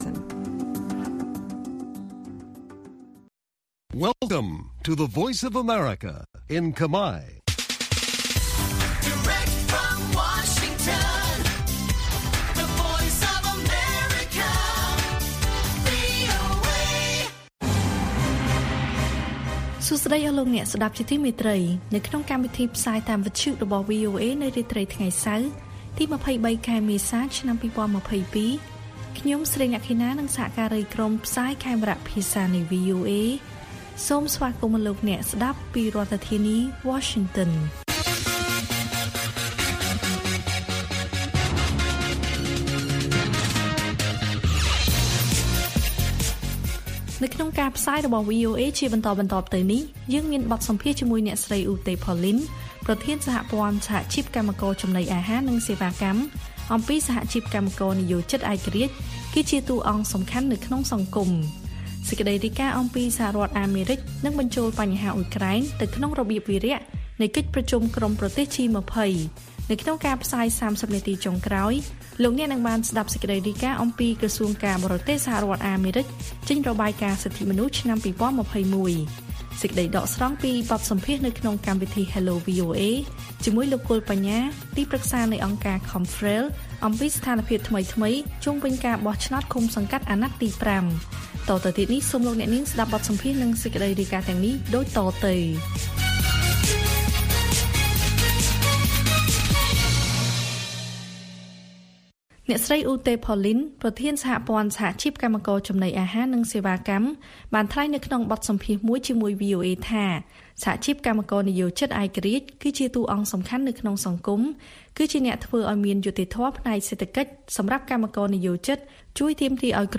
ព័ត៌មានពេលរាត្រី ២៣ មេសា៖ បទសម្ភាសន៍អំពីតំណាងសហជីពថា គ្មានសហជីពឯករាជ្យបង្ហាញថាប្រទេសគ្មានប្រជាធិបតេយ្យ